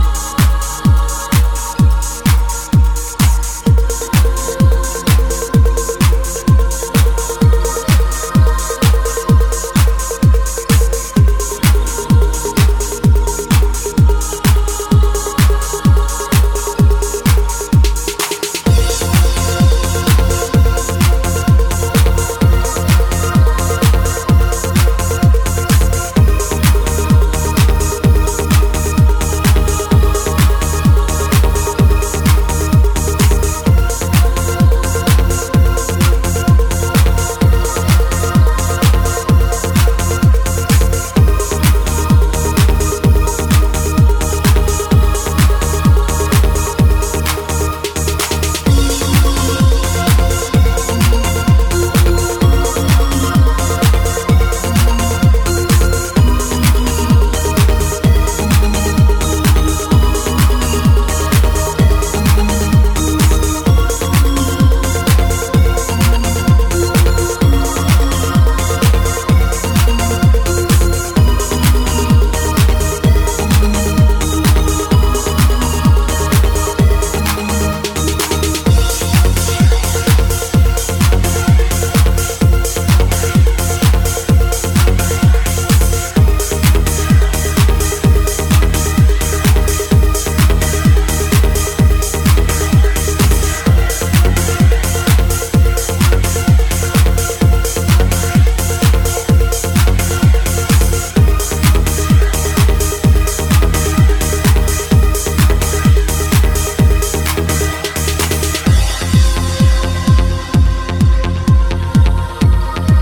zero swing drums and lately bass rhythms
intense synth stabs